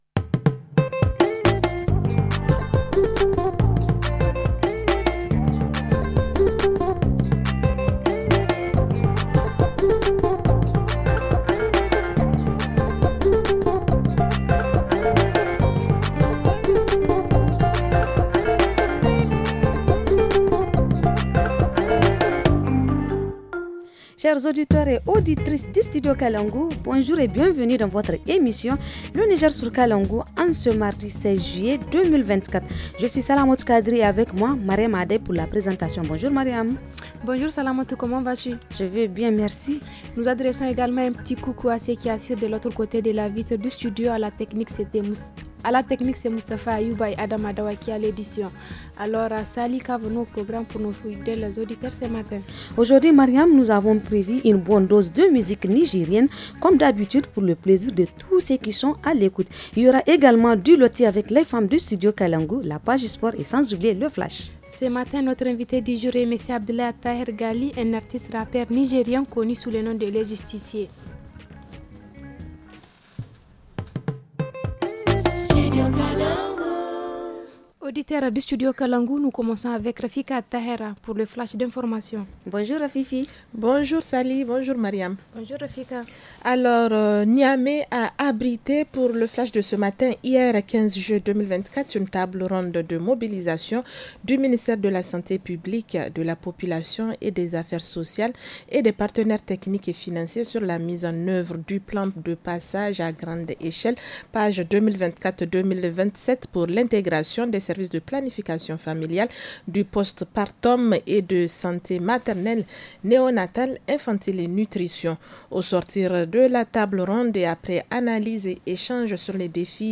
Comment se manifeste le pied bot chez les enfants ? Zoom sur le Tchegeni et le Kidi, des tam-tams traditionnels en milieu Toubou.